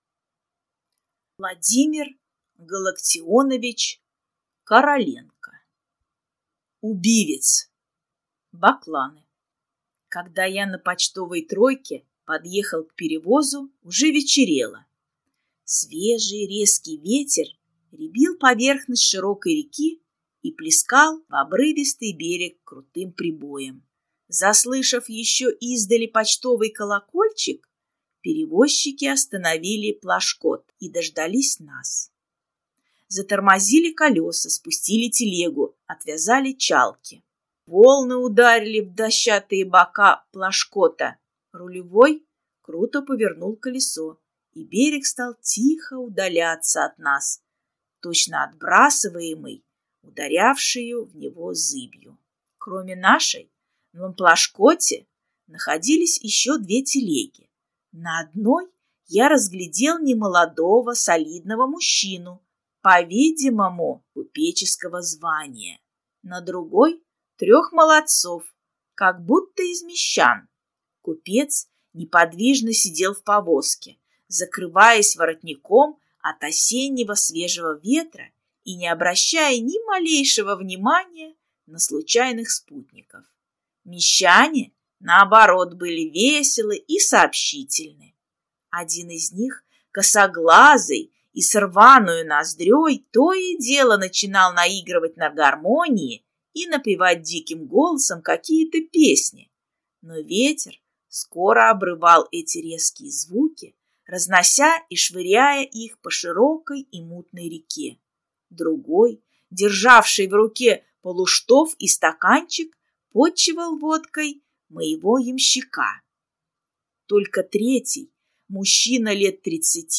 Аудиокнига Убивец | Библиотека аудиокниг